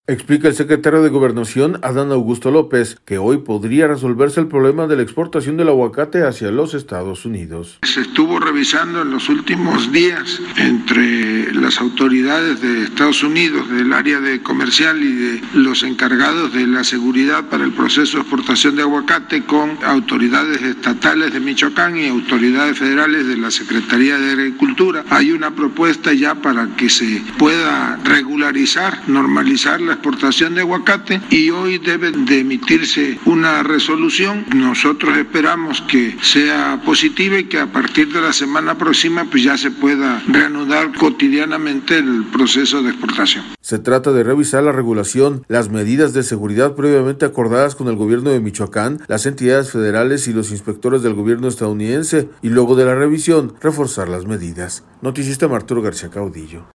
Explica el secretario de Gobernación, Adán Augusto López, que hoy podría resolverse el problema de la exportación del aguacate hacia los Estados Unidos.